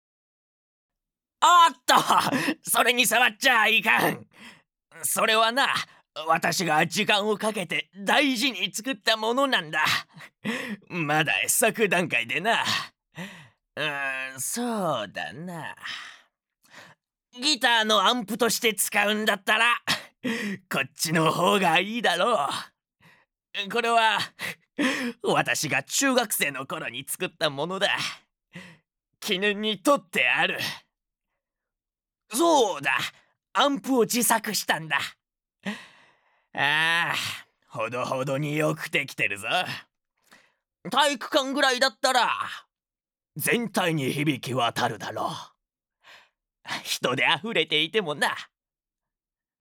ボイスサンプル
●セリフ④60歳前後のコミカルおじいさん